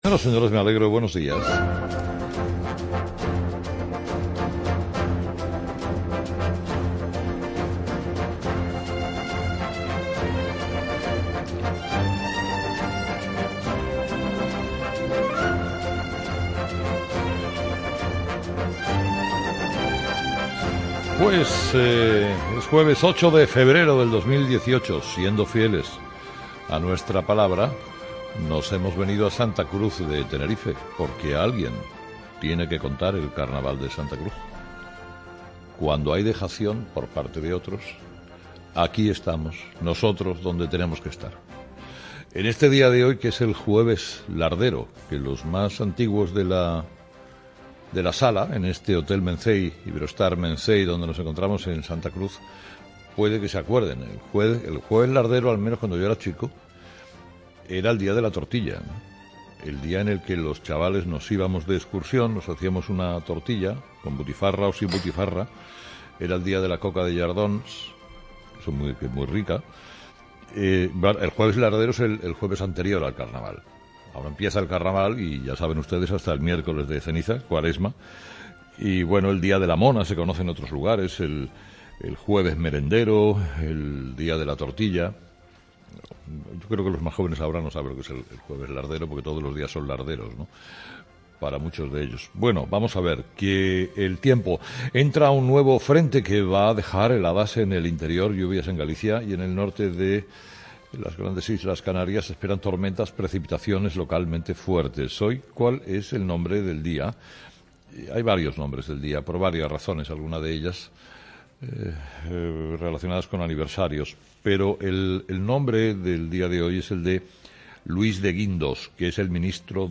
Herrera desde Tenerife